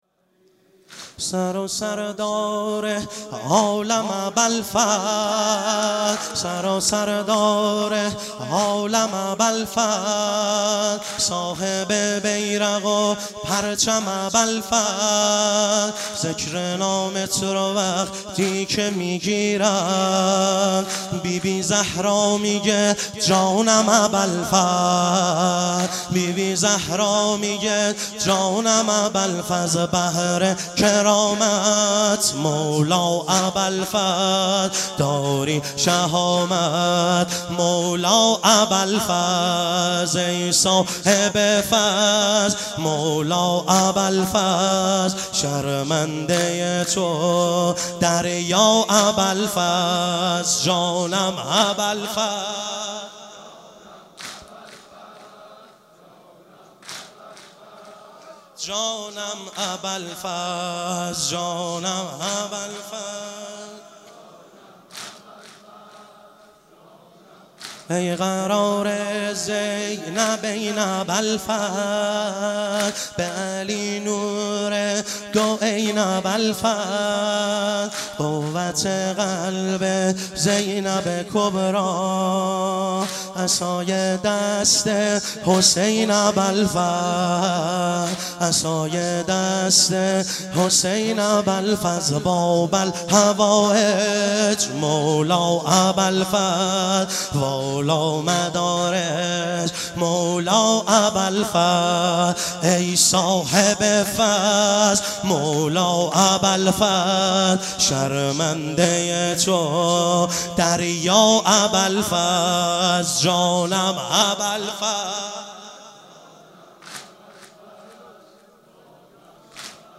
مشهد الرضا - واحد